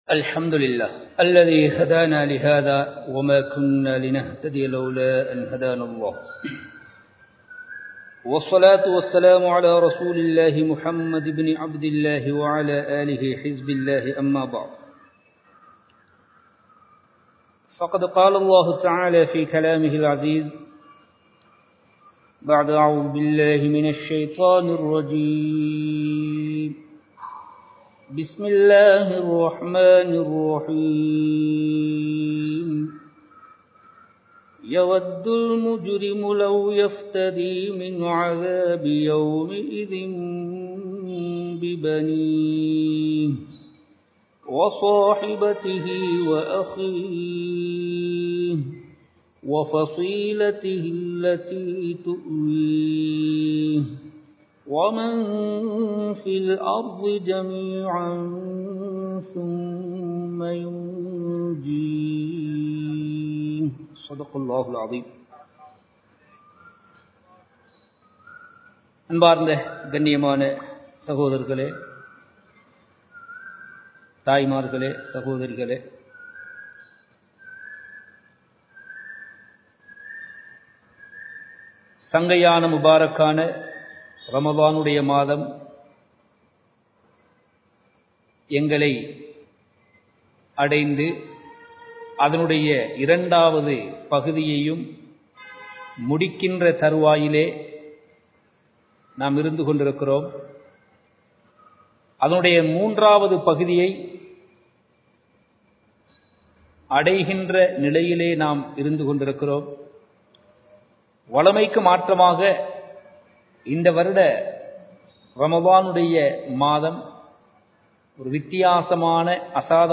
நரகம் ( Hell) | Audio Bayans | All Ceylon Muslim Youth Community | Addalaichenai
Live Stream